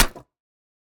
Minecraft Version Minecraft Version 25w18a Latest Release | Latest Snapshot 25w18a / assets / minecraft / sounds / block / mud_bricks / break1.ogg Compare With Compare With Latest Release | Latest Snapshot
break1.ogg